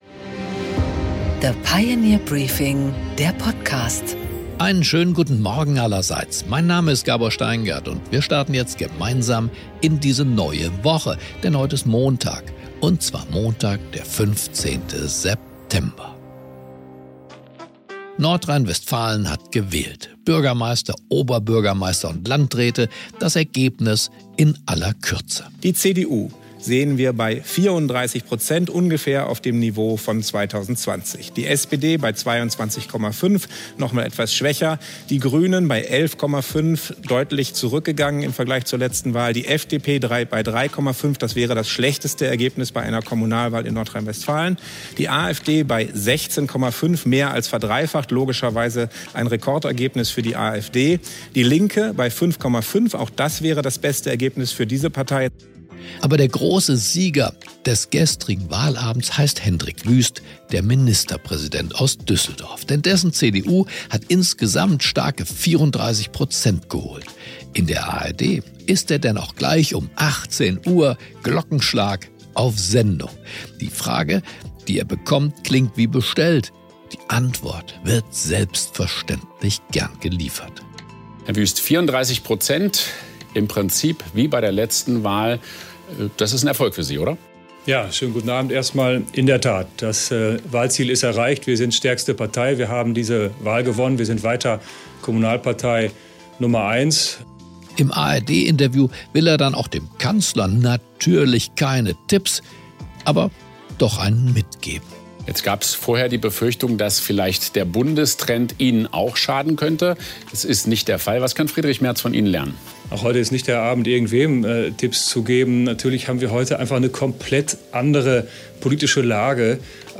Gabor Steingart präsentiert das Pioneer Briefing
Im Gespräch: Carsten Linnemann, CDU-Generalsekretär, spricht mit Gabor Steingart über die Konsequenzen des NRW-Wahlergebnisses und die Bedeutung für den Bund.